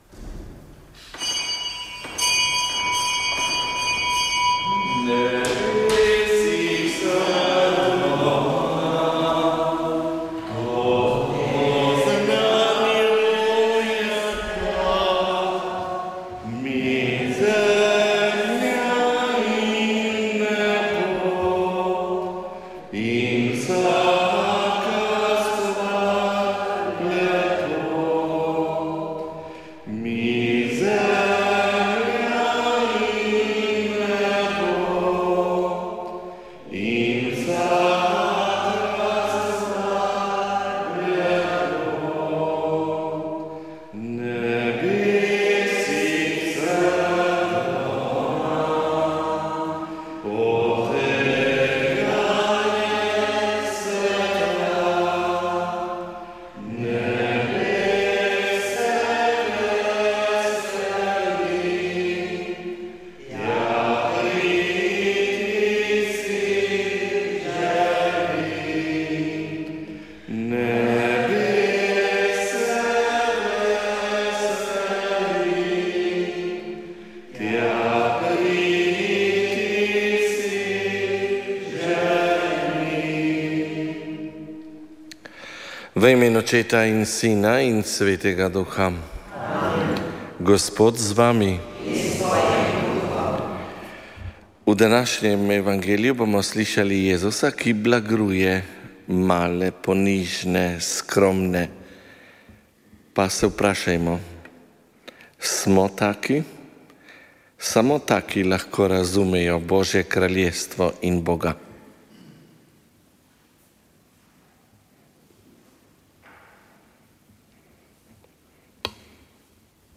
Sv. maša iz bazilike Marije Pomagaj na Brezjah 6. 5.